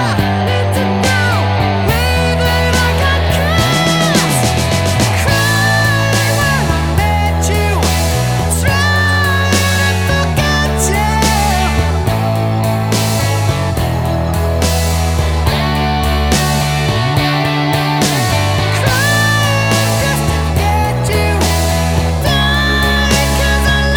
No Lead Guitar Rock 5:26 Buy £1.50